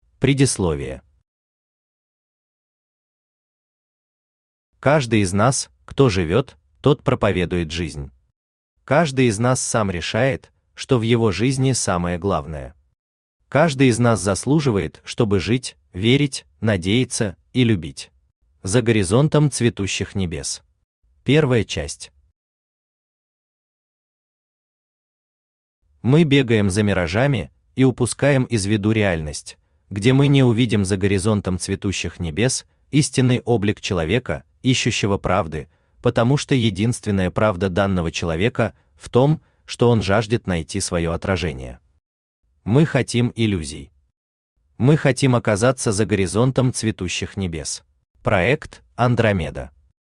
Аудиокнига Проповедуя жизнь. Сборник | Библиотека аудиокниг
Сборник Автор Виталий Александрович Кириллов Читает аудиокнигу Авточтец ЛитРес.